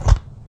PixelPerfectionCE/assets/minecraft/sounds/mob/sheep/step1.ogg at mc116